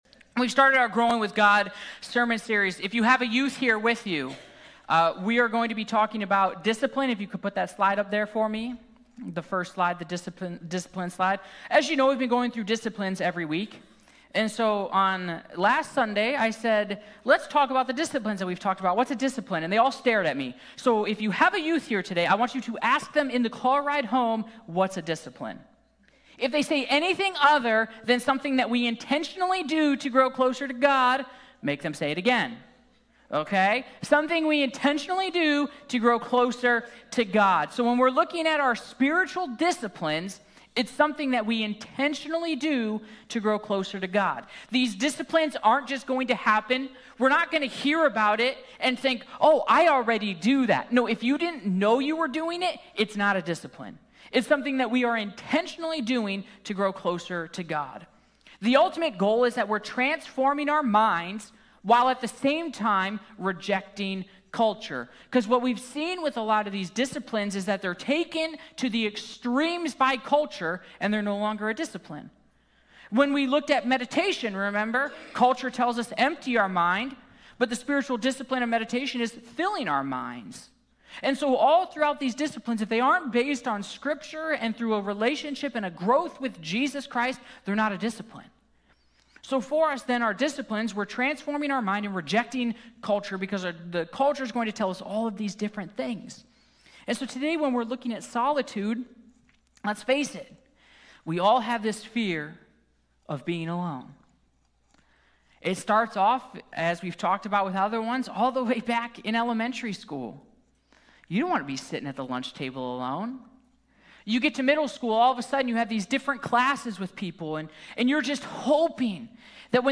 In this week’s message